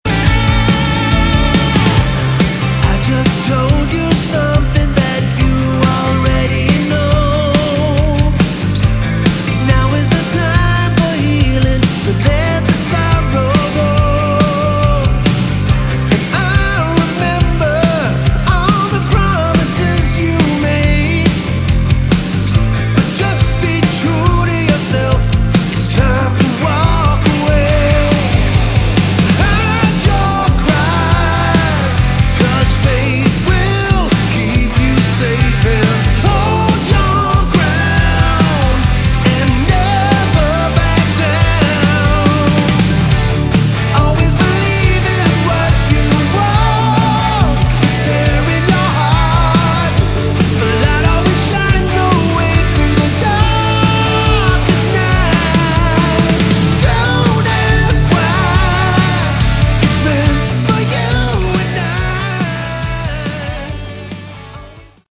Category: Melodic Rock
lead vocals, bass, keyboards
drums
backing vocals